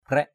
/krɛʔ/ (d.) cây lim thần, lim xanh.
kraik.mp3